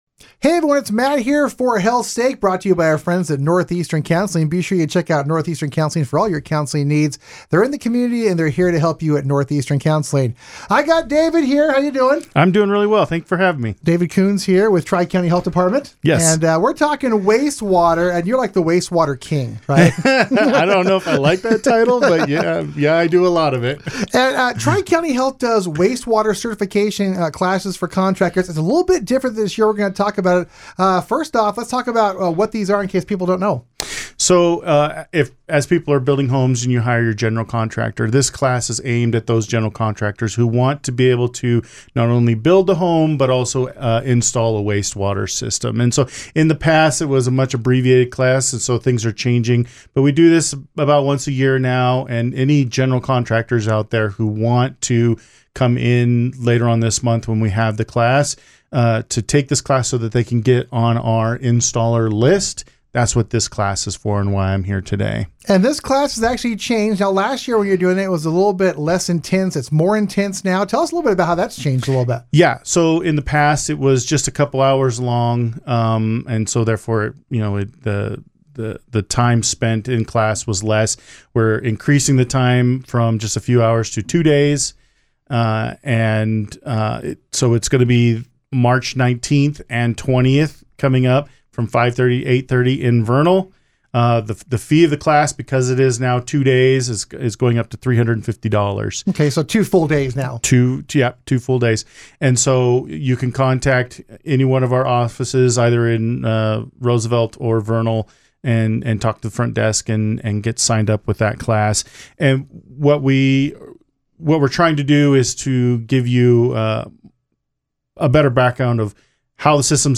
Weekly Radio Spots